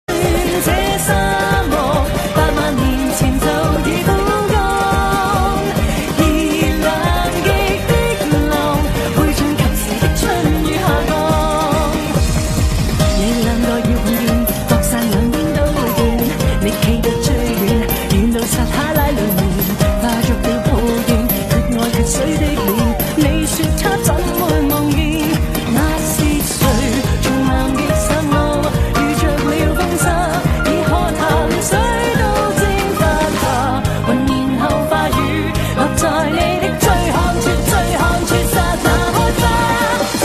华语歌曲
粤语